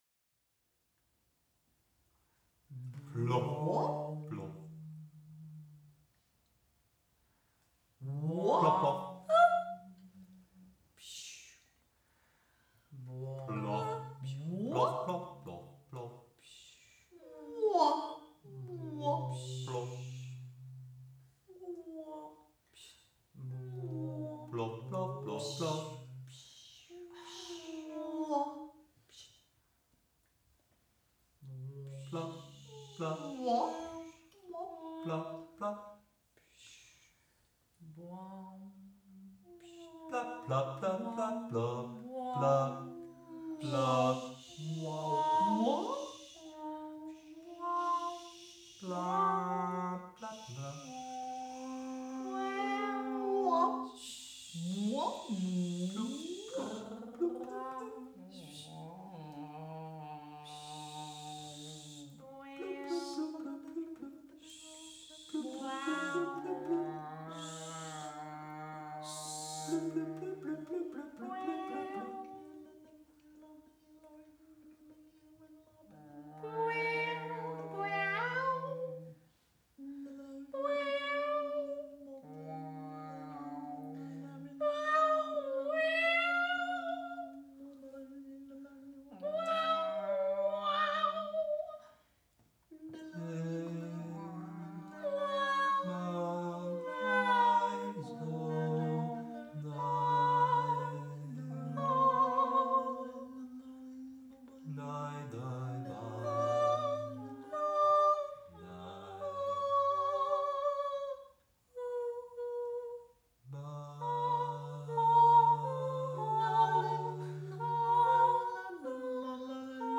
focussed on improvisation
one in Studio Loos in The Hague